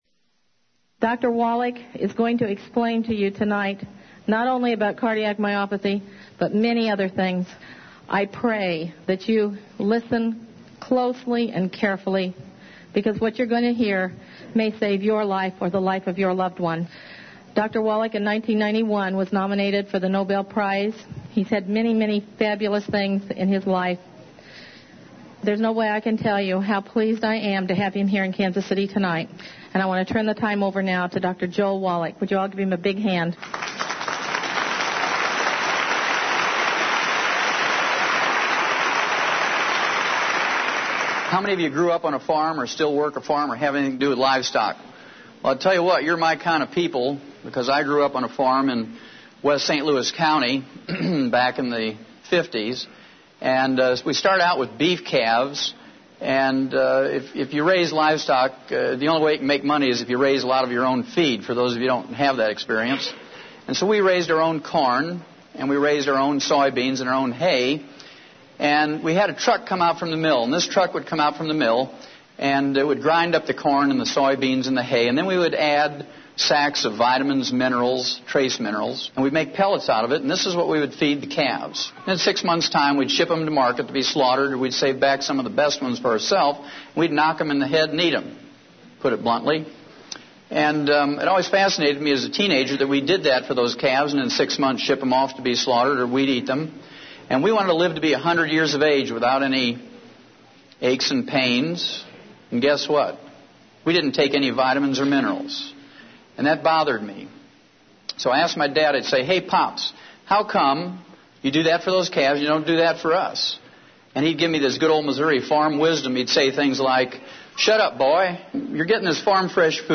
AUDIO: The Original Dead Doctors Don't Lie Lecture